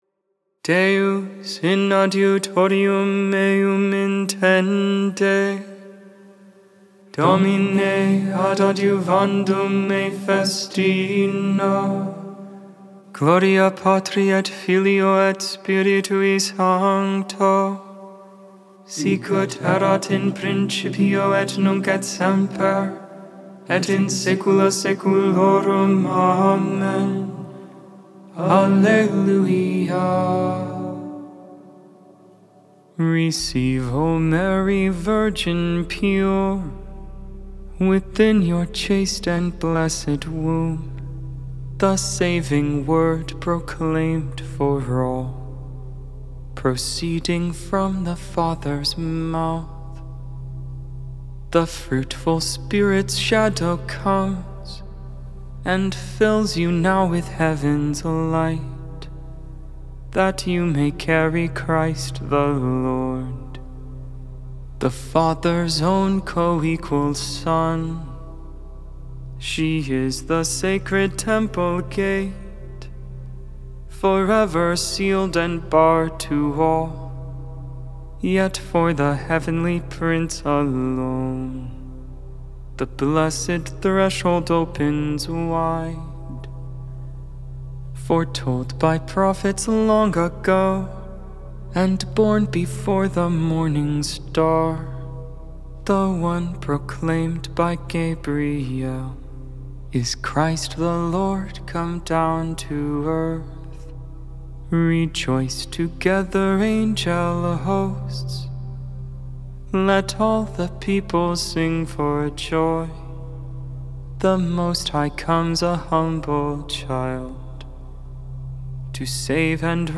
(mode II)Ant.